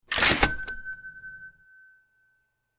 Cash Register Purchase
Cash-Register-Purchase-Sound-Effects-Download-.mp3